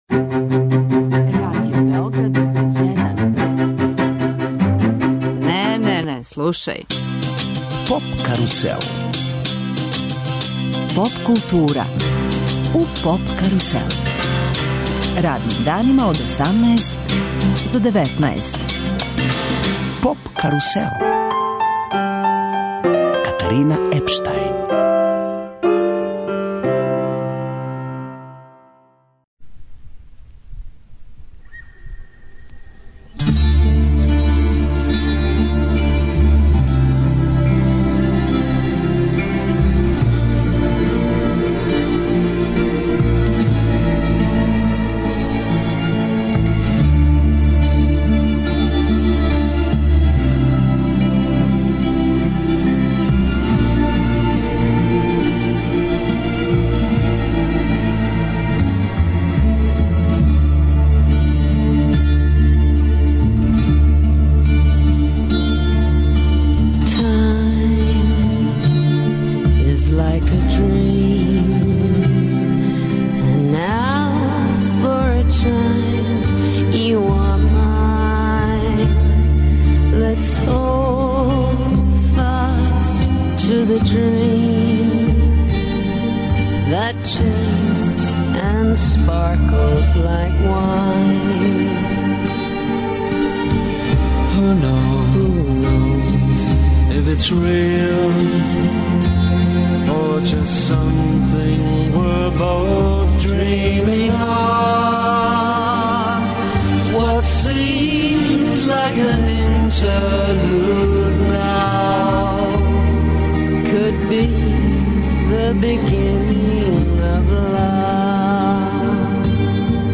Гости емисије су гитариста Зеле Липовача